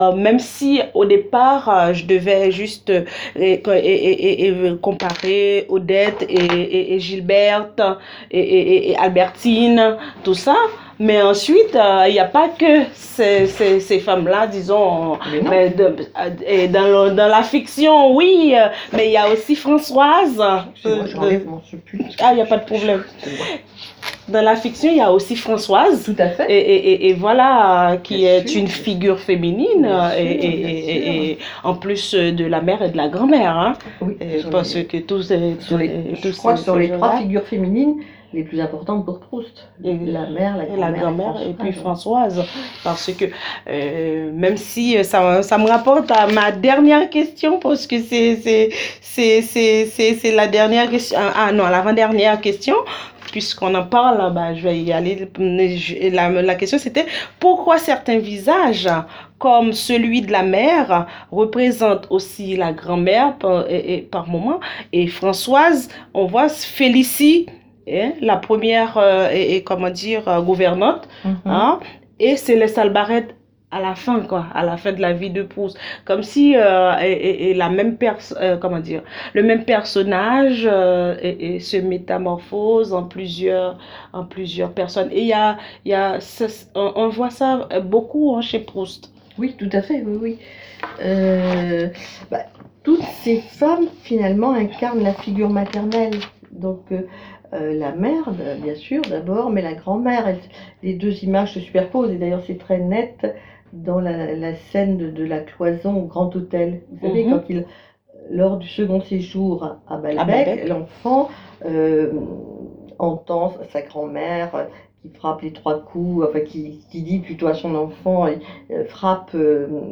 Entretien
entretien enregistré à la bibliothèque de la Sorbonne, sur les figures féminines chez Proust.